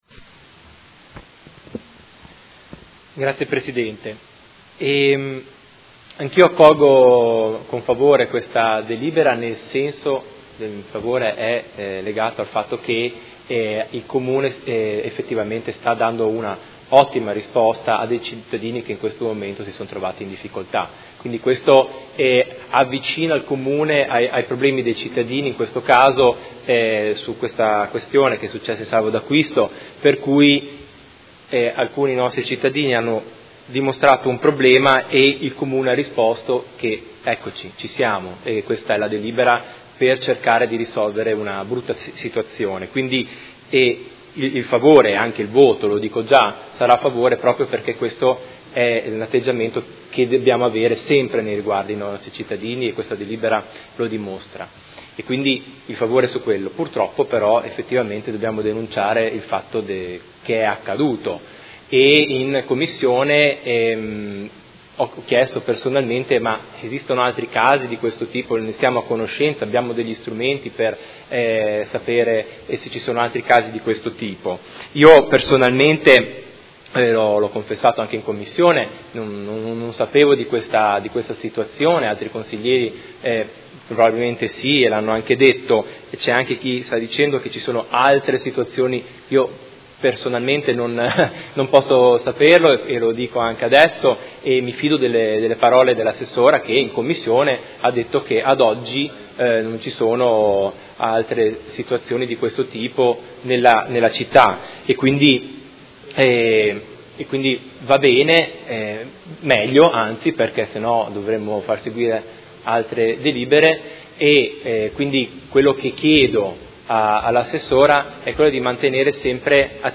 Marco Chincarini — Sito Audio Consiglio Comunale